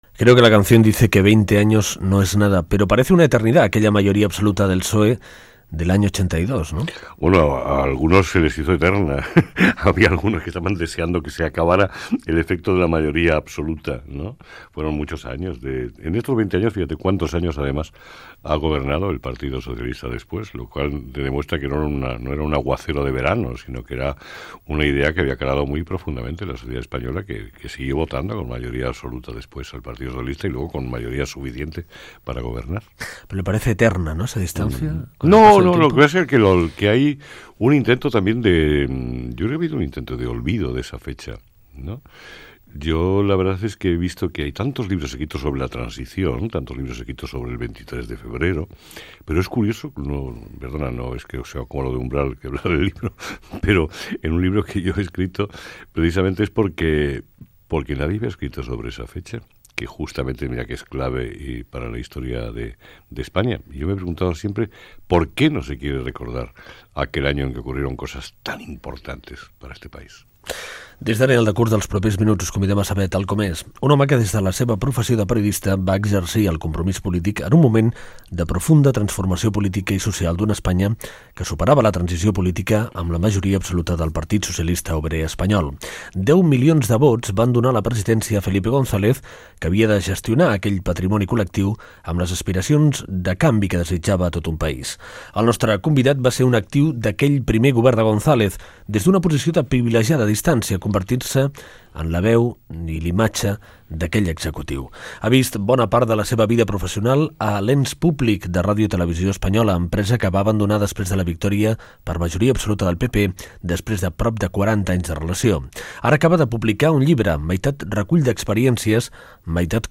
Fragment d'una entrevista al periodista Eduardo Sotillos.
Entreteniment
FM